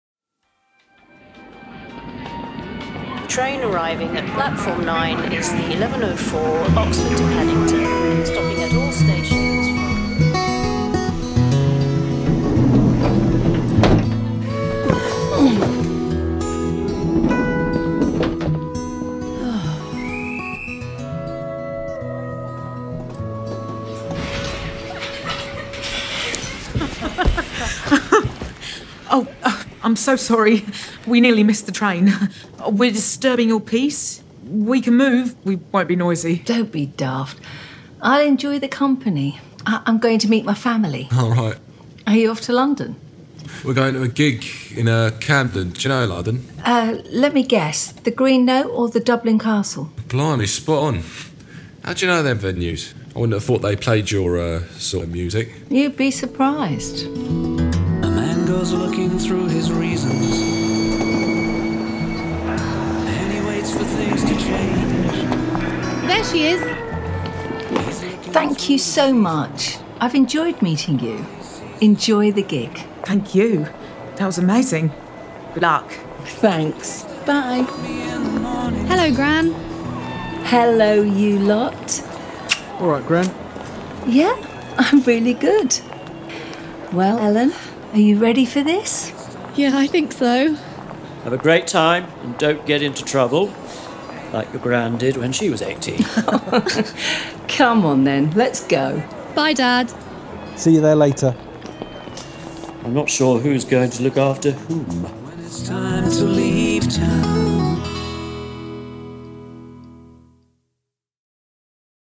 PLAYING WOODSTOCK – Audio Play
This was originally recorded as a table read, in a room with less-than-ideal acoustics. A narrator read the scenes and action lines. The audio was all comped from 2 takes, edited, and all narration, taken out. Some additional dialogue was then recorded remotely and matched in. Music, Foley and SFX were then added to complete a very ‘visual’ audio play.